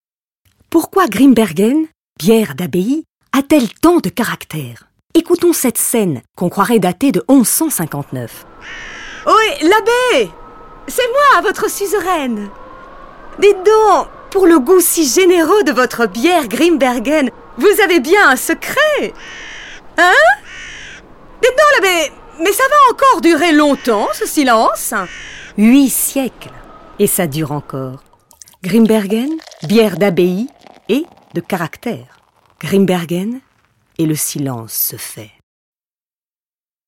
Pub Bière